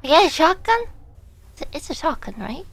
Worms speechbanks
Collect.wav